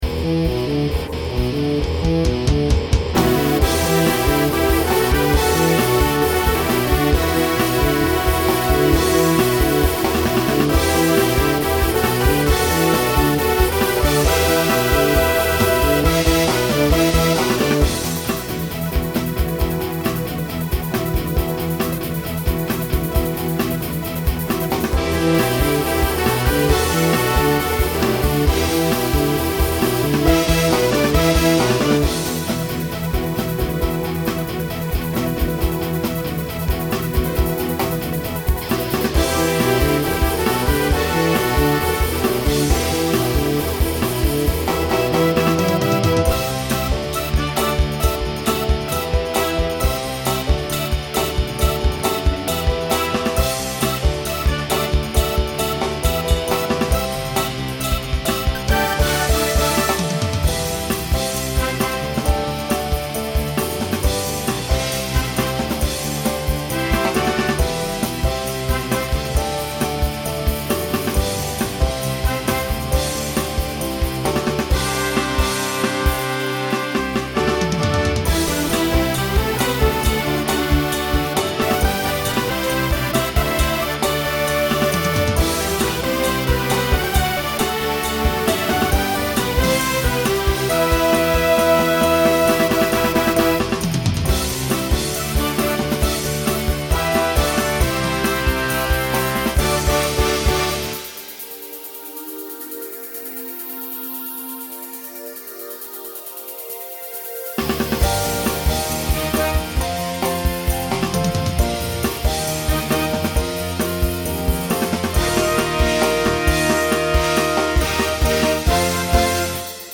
Voicing SSA Instrumental combo Genre Rock